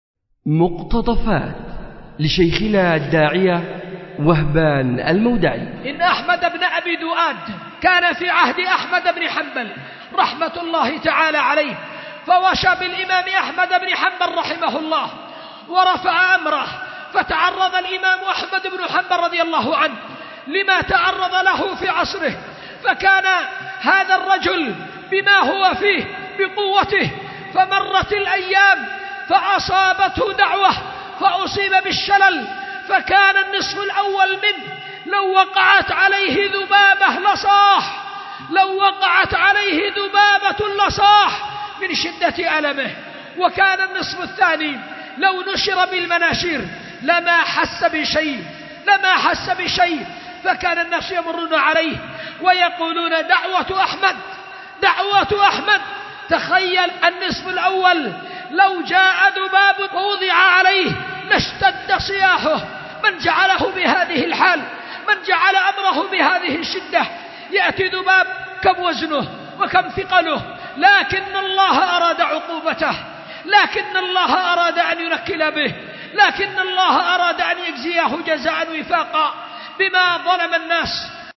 أُلقي بدار الحديث للعلوم الشرعية بمسجد ذي النورين ـ اليمن ـ ذمار ـ 1444هـ